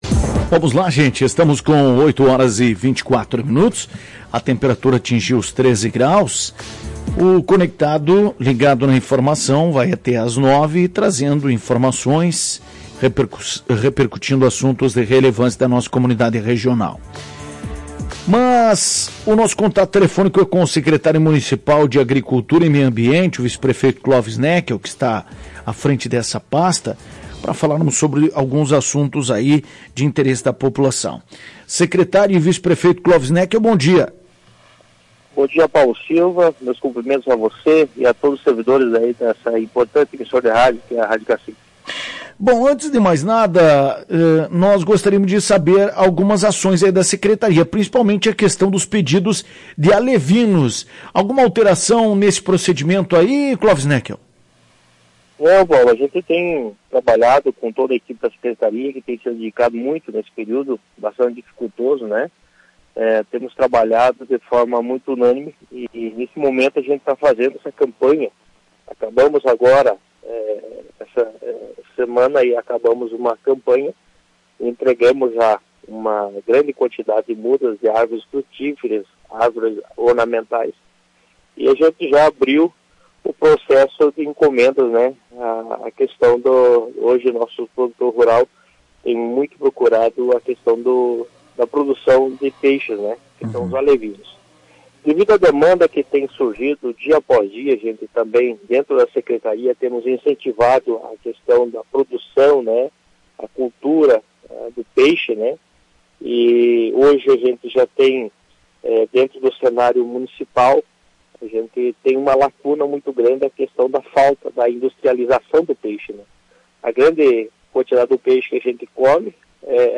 Na manhã desta sexta-feira em entrevista à Tua Rádio, o Secretário Municipal de Agricultura e Vice-Prefeito, Clóvis Neckel, reforçou a importância de proprietários rurais se prepararem com reservatórios de água para enfrentar futuras estiagens.